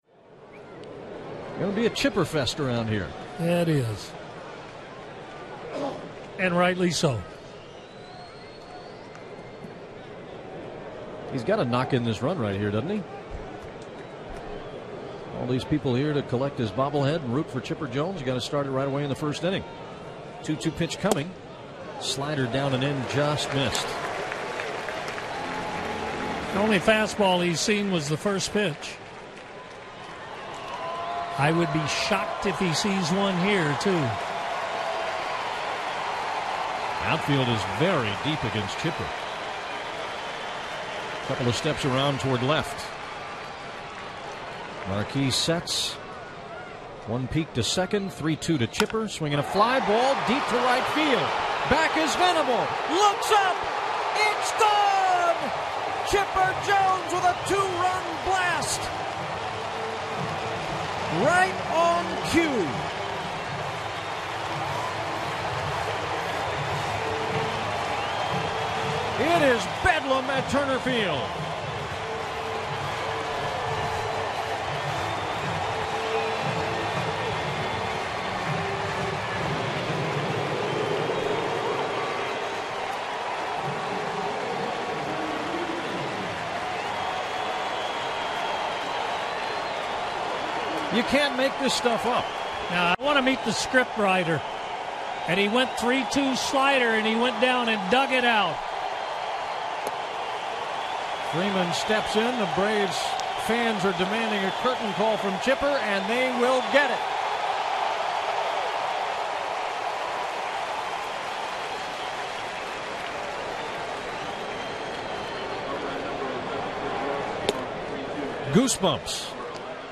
August 16, 2012 vs. SD - Chipper Jones celebrates his bobblehead night in style. Jim Powell and Don Sutton have the call on the Braves Radio Network.